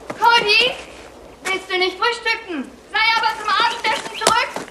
Im Original wurden beide Rollen von der selben Person eingesprochen. Klingt hier ebenso.